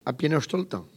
Collectif patois et dariolage
Catégorie Locution